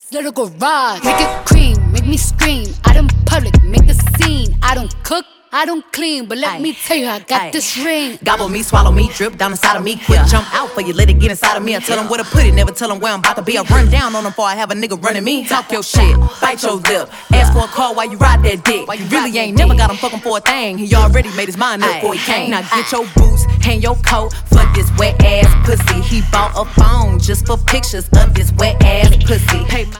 • Hip-Hop/Rap
aggressive flow and candid lyrics